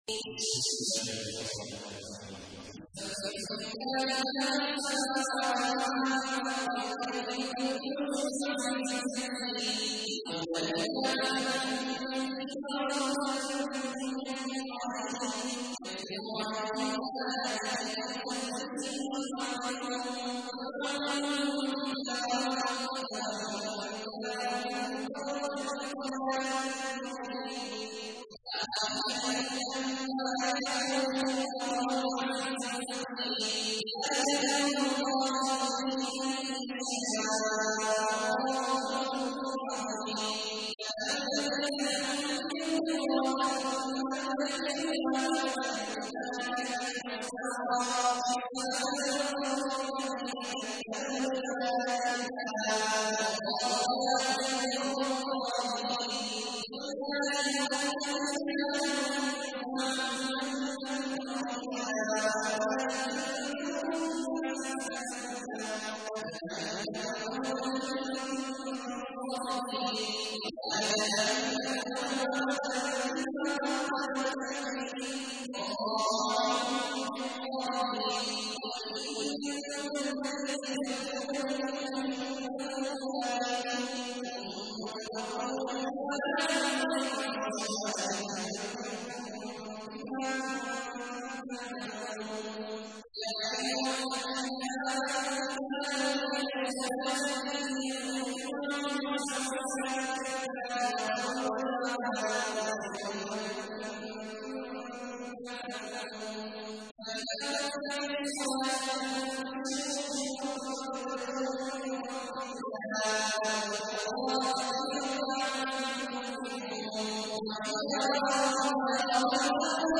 تحميل : 62. سورة الجمعة / القارئ عبد الله عواد الجهني / القرآن الكريم / موقع يا حسين